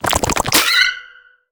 Sfx_creature_penguin_skweak_01.ogg